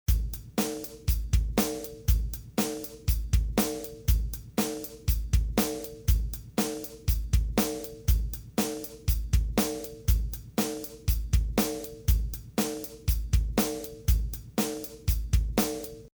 drums.mp3